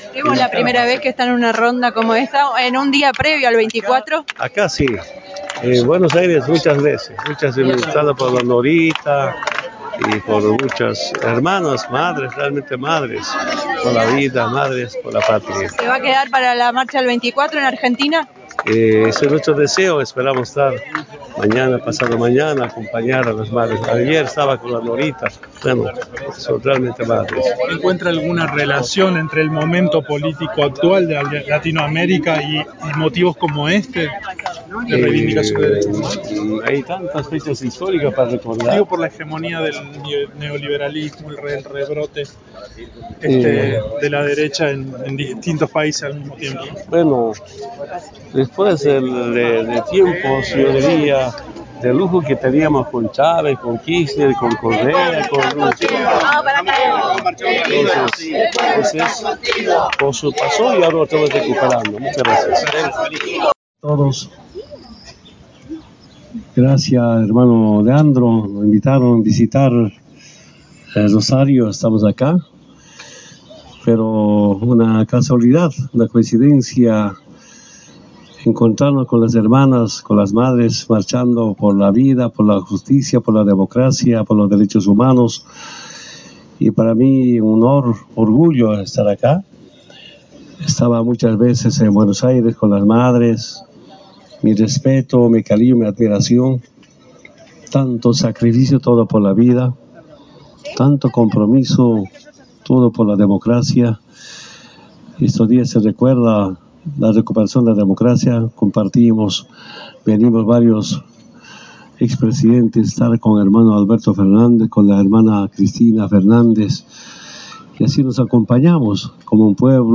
El ex presidente boliviano estuvo en la plaza 25 de mayo para participar de la ronda de las madres, en la víspera de un nuevo Día de la Memoria y afirmó a Cadena 3 Rosario que es algo “histórico”.